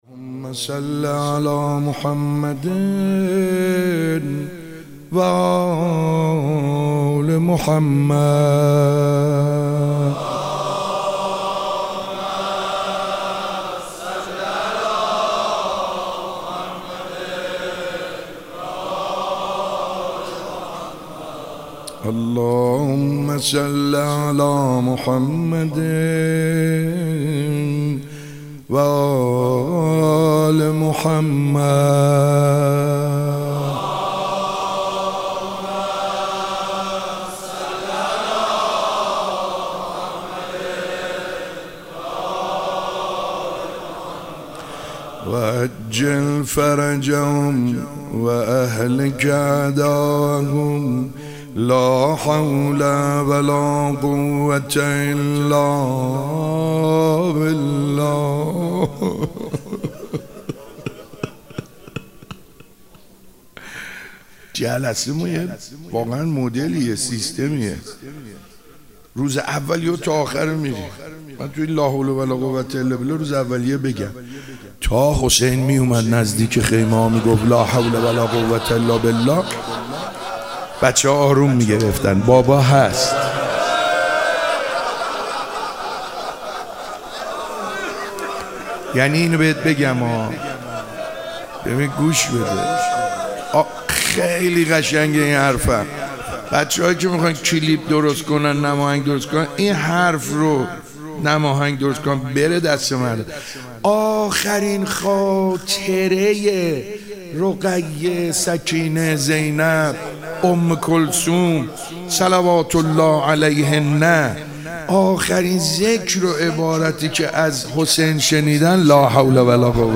شب دوم محرم۹۸ مهدیه امام حسن مجتبی (ع) تهران
روضه